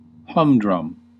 Ääntäminen
IPA: [ˈlaŋ.vaɪ.lɪk] : IPA: [ˈlaŋ.vaɪ.lɪç]